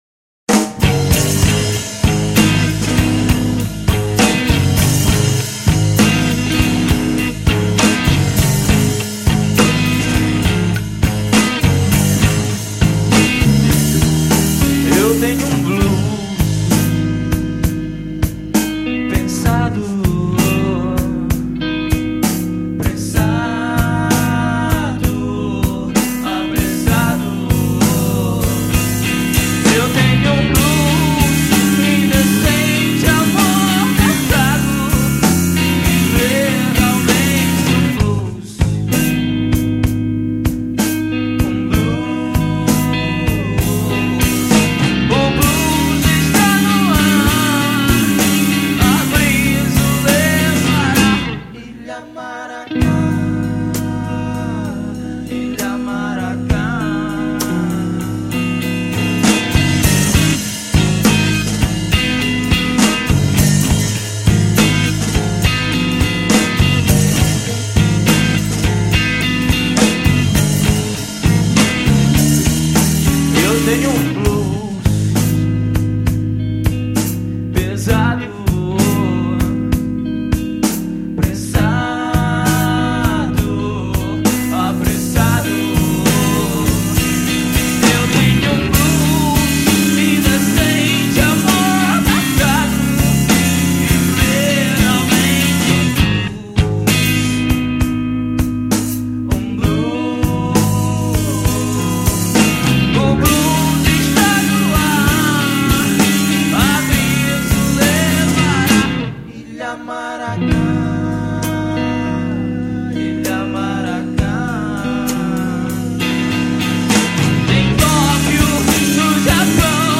1718   03:34:00   Faixa:     Rock Nacional